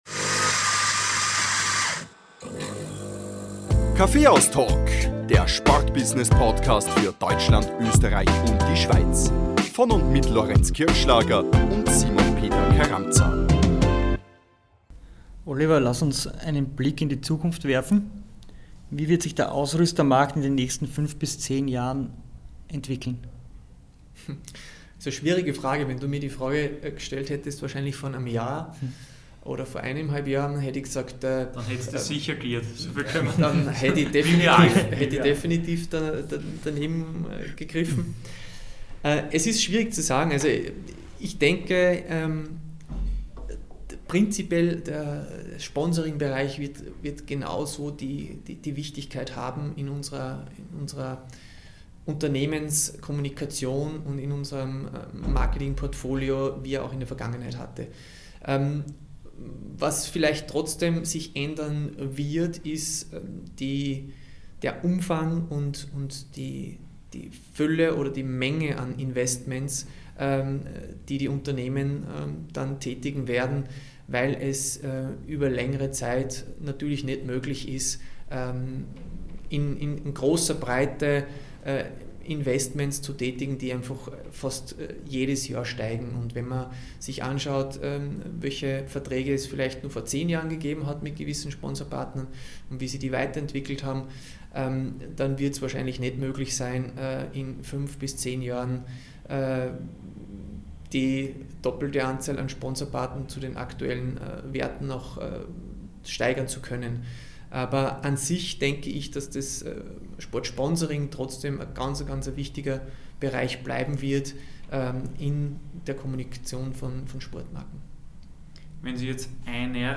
TIPPS, TRENDS UND WORDRAP